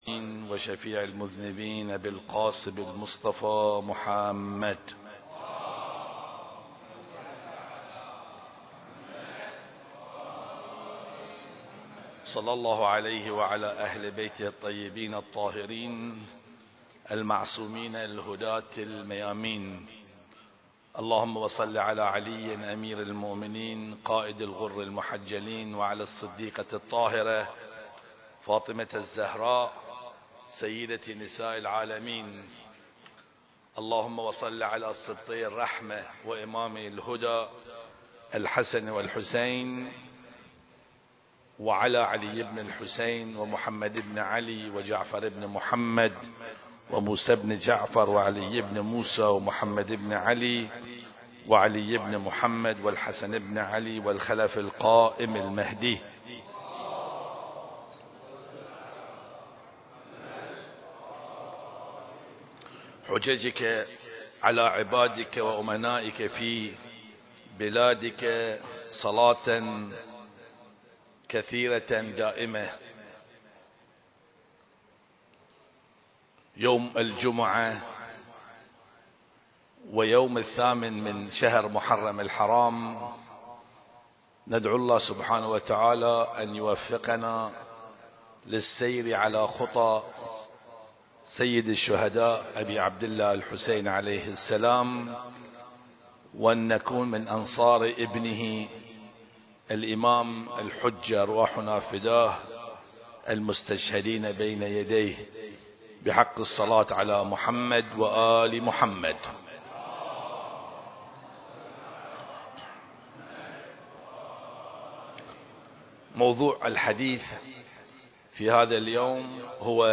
المكان: مسجد الغدير - البحرين